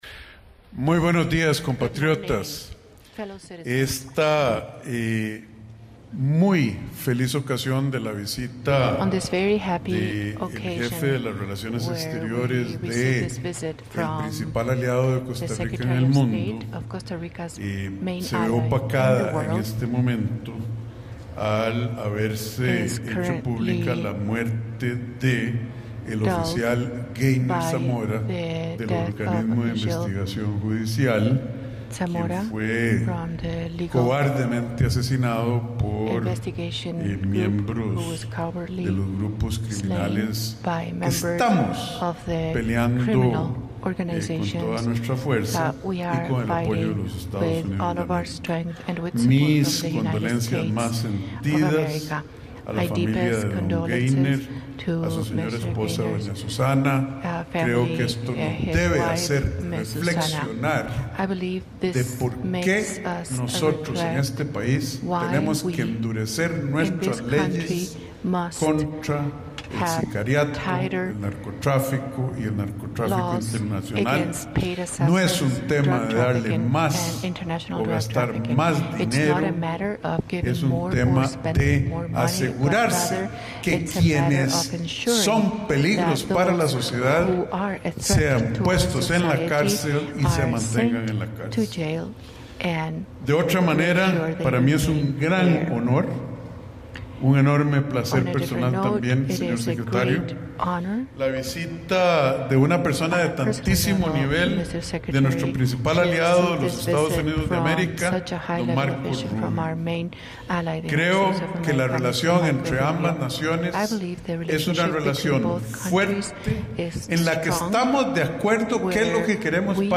Marco Rubio: Joint Presser with Costa Rica President Rodrigo Chaves Robles (transcript-audio-video)